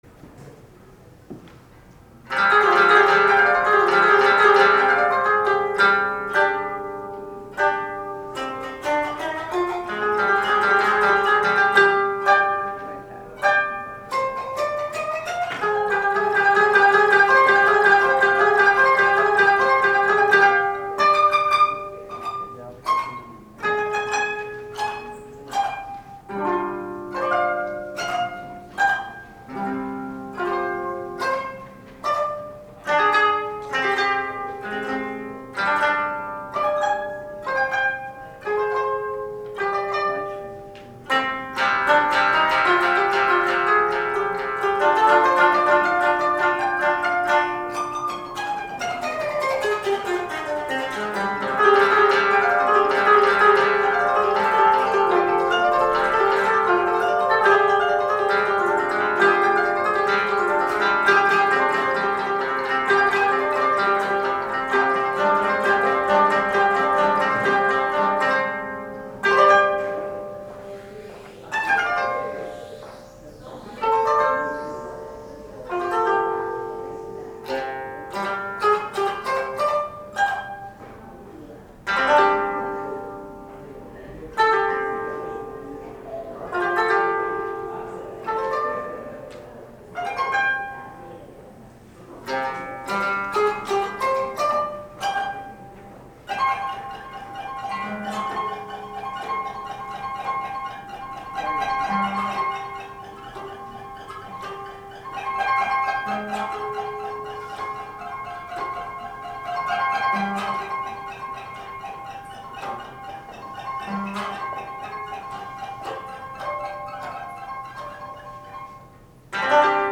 ◎　「ロンドンの夜の雨」ライブ